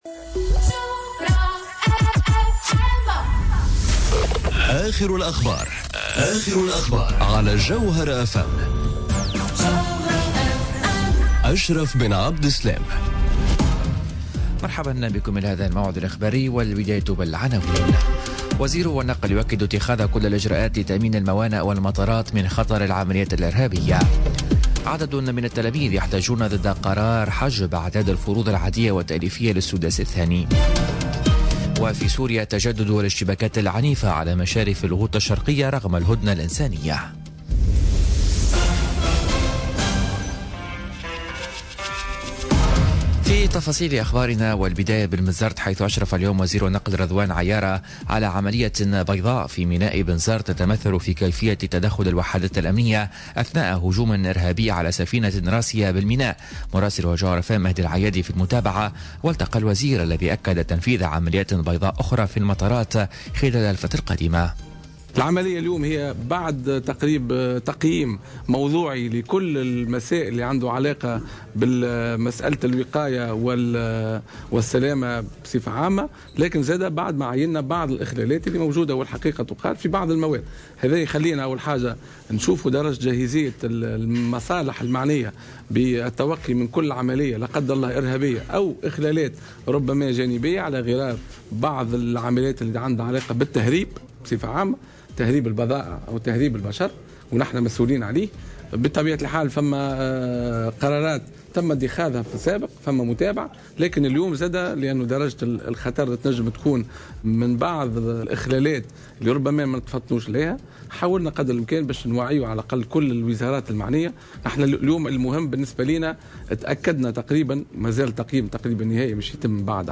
نشرة أخبار منتصف النهار ليوم الأربعاء 28 فيفري 2018